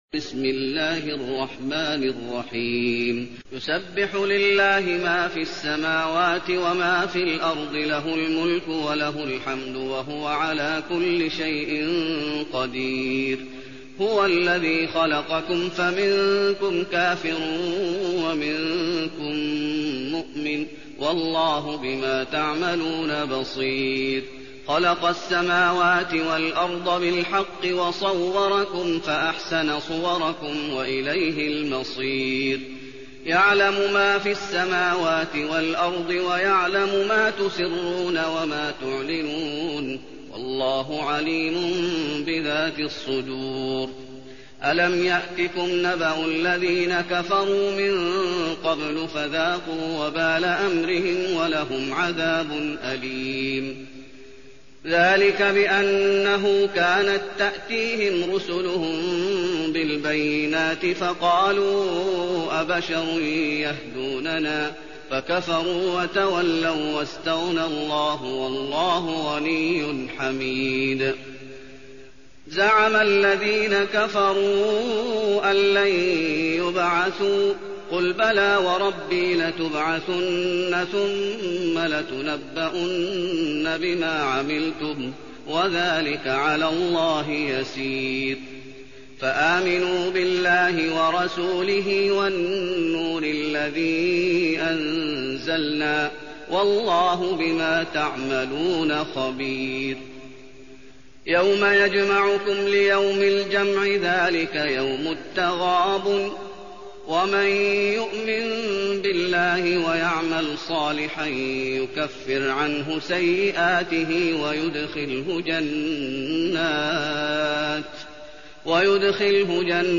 المكان: المسجد النبوي التغابن